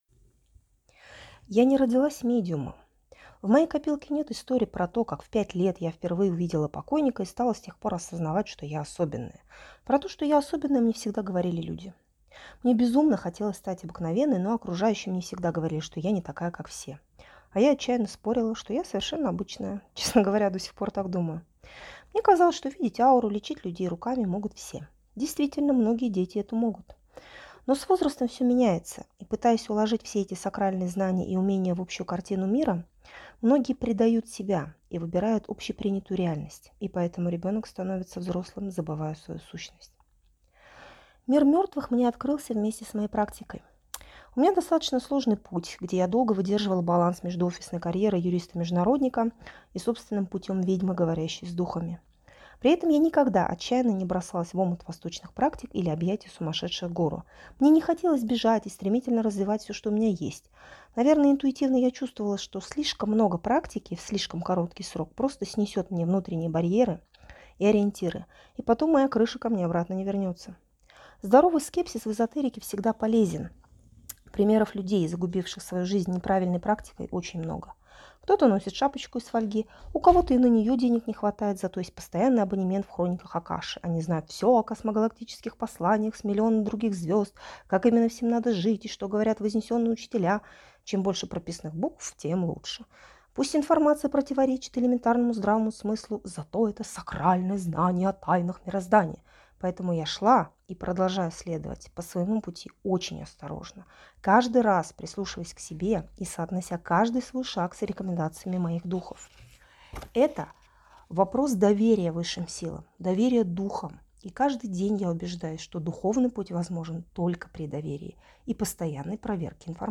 Аудиокнига Мир мертвых. Все о смерти и Другой стороне от практикующего медиума | Библиотека аудиокниг